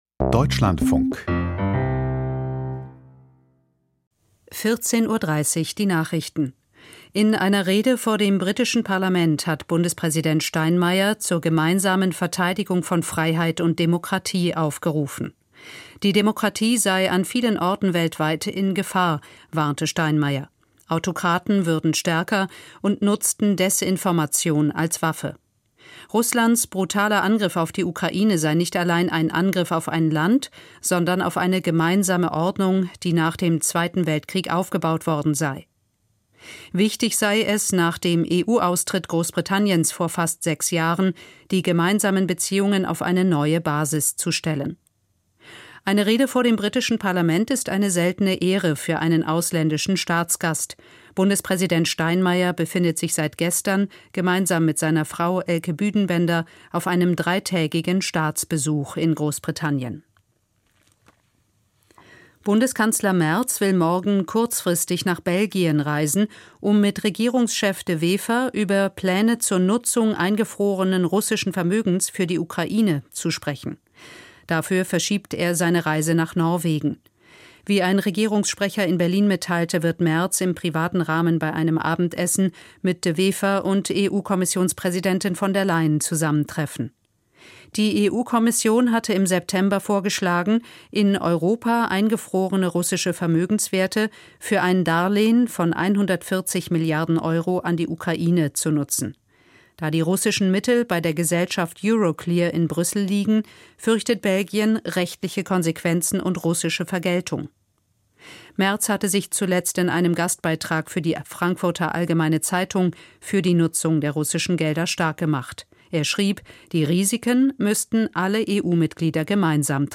Die Nachrichten vom 04.12.2025, 14:30 Uhr